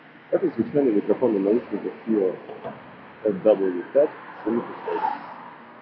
Микрофон FiiO FW5 на 5 из 10, он меня расстроил, он на уровне бюджетников за 30-40$, особенно в шумных условиях.
В шумных условиях:
fiio-fw5-shum.m4a